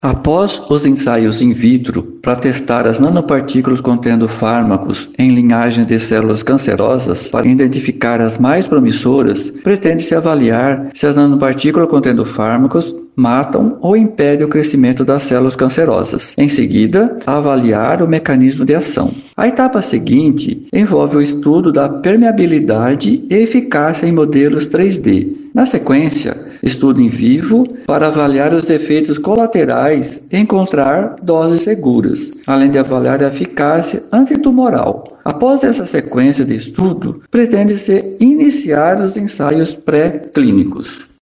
Em entrevista à FM 104.7